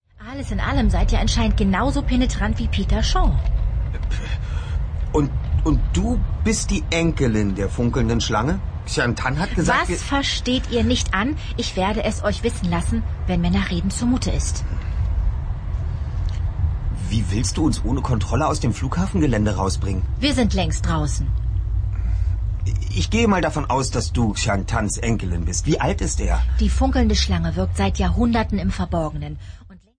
hell, fein, zart, sehr variabel
Lip-Sync (Synchron)